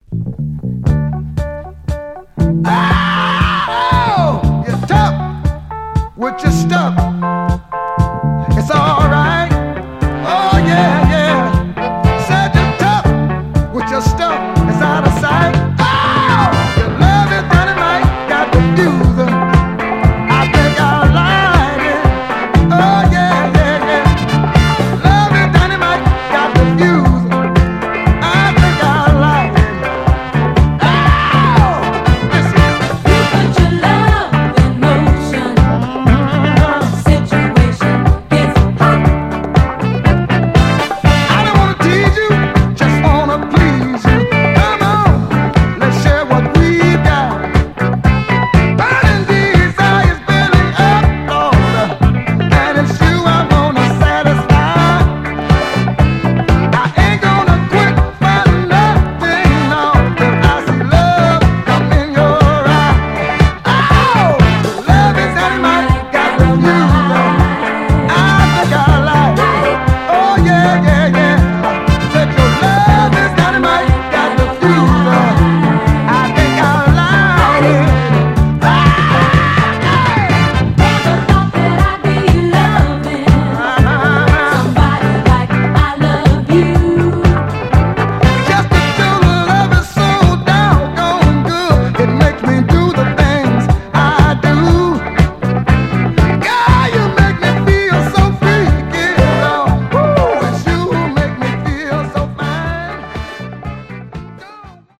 キャッチーで飄々とした使い勝手の良いパーカッシヴ・デトロイト・ブギー/ディスコ〜ファンク・ダンサーです！
※試聴音源は実際にお送りする商品から録音したものです※